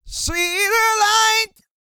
E-GOSPEL 109.wav